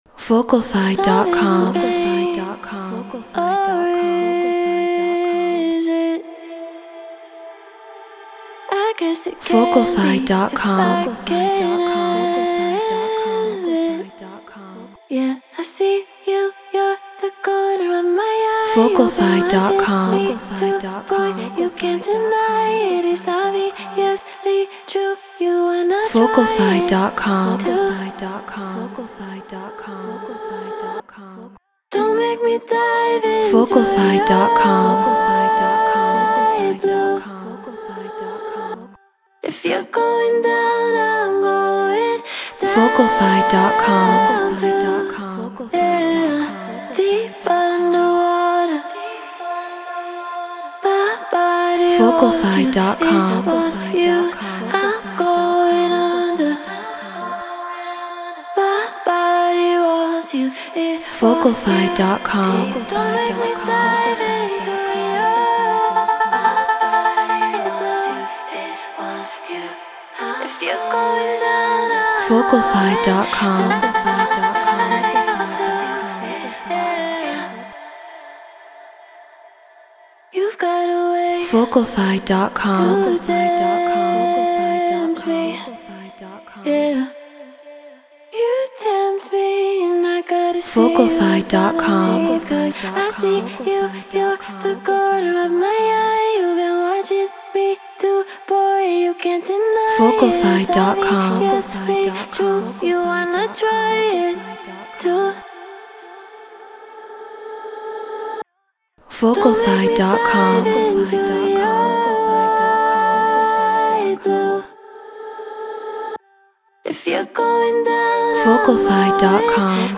House 124 BPM Dmin
Shure SM7B Scarlett 2i2 4th Gen Ableton Live Treated Room